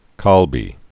(kälbē) or gal·bi (gäl-)